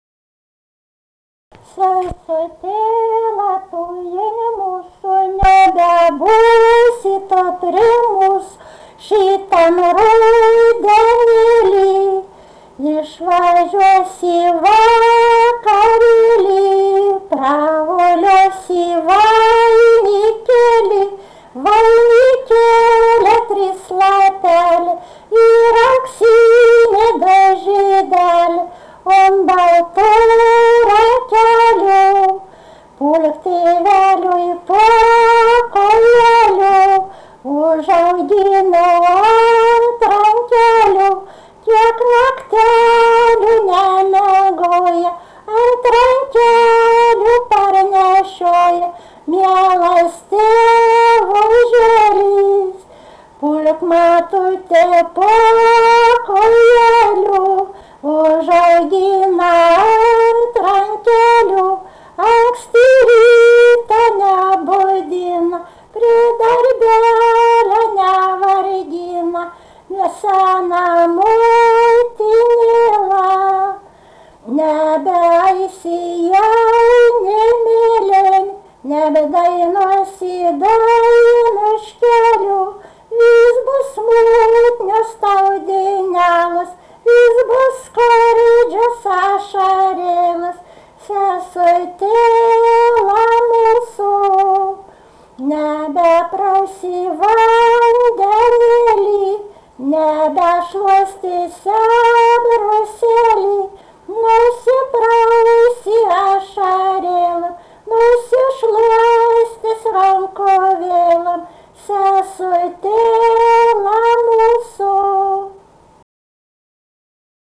daina, vestuvių
vokalinis